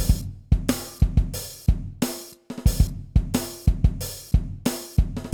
acro_drumloop3_90.WAV